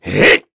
Crow-Vox_Attack2.wav